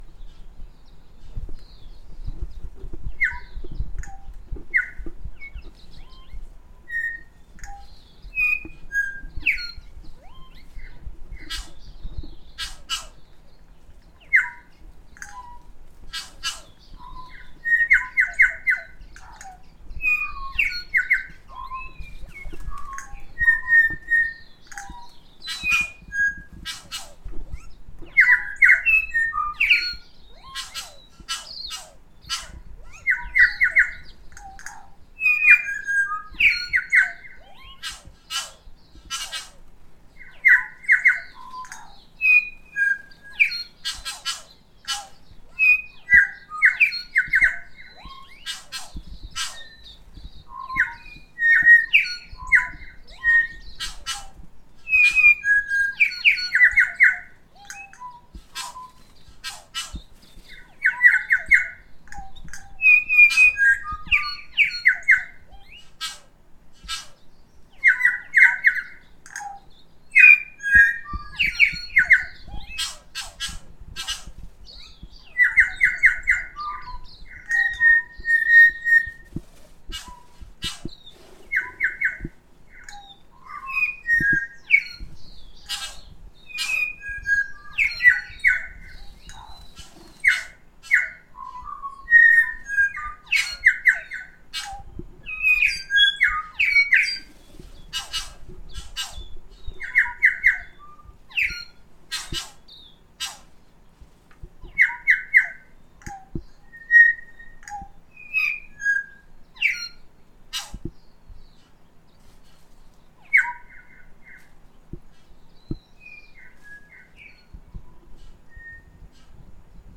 Were there any korimako/NZ bellbirds in our garden, you might ask?
At least six at once, and singing up a storm.